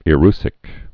(ĭ-rsĭk)